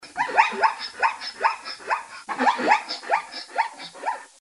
Звуки зебры: Визг маленького детеныша